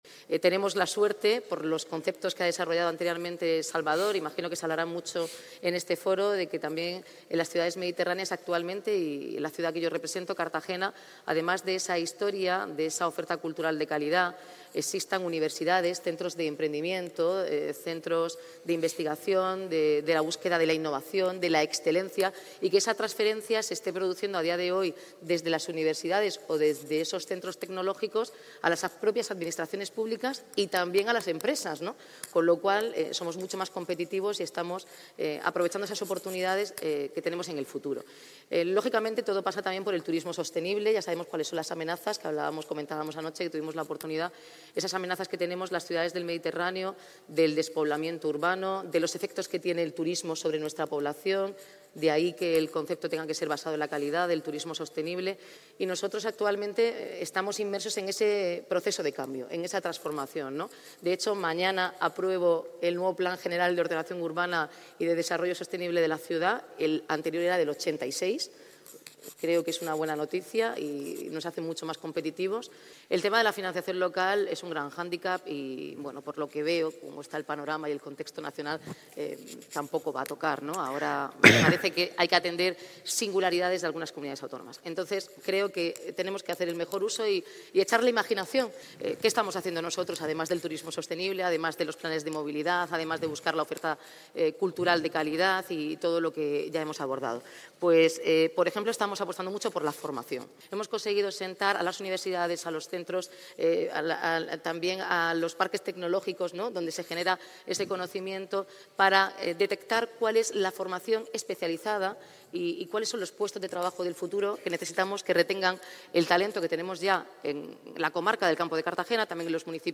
La alcaldesa, Noelia Arroyo, en el Foro Económico y Social del Mediterráneo celebrado en Valencia